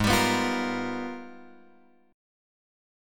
G#mM13 chord {4 x 5 4 6 6} chord